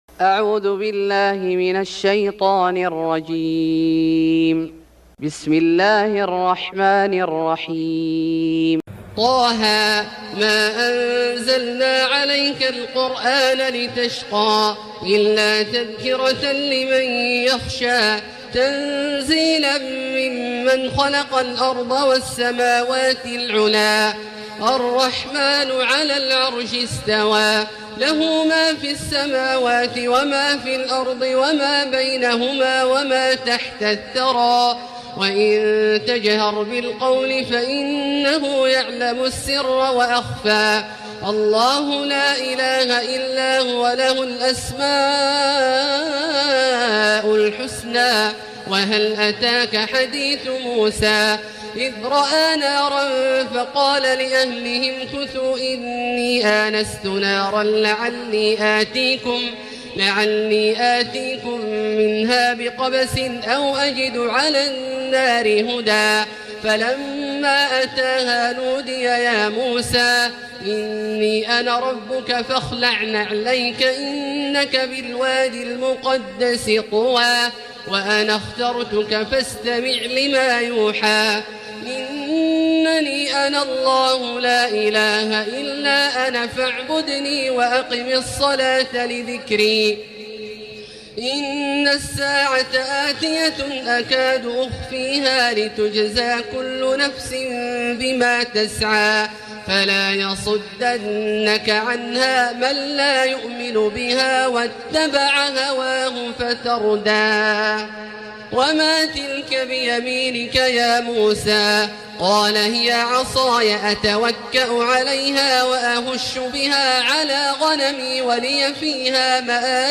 سورة طه Surat Ta-Ha > مصحف الشيخ عبدالله الجهني من الحرم المكي > المصحف - تلاوات الحرمين